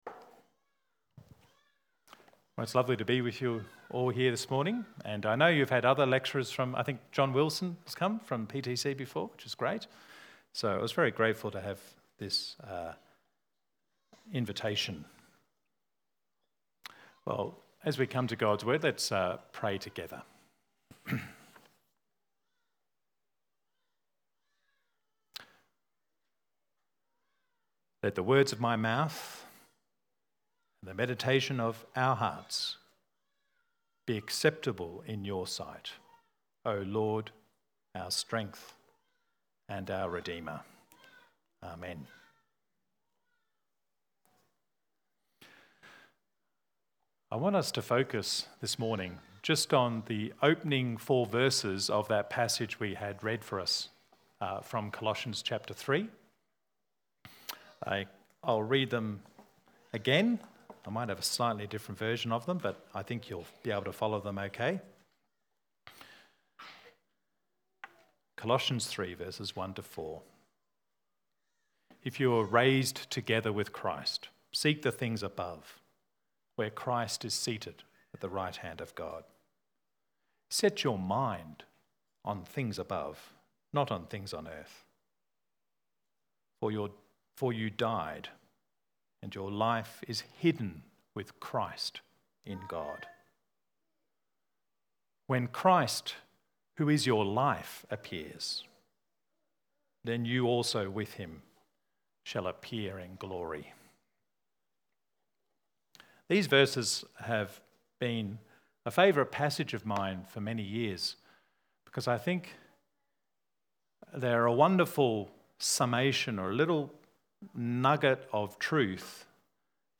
Church-Sermon-220226.mp3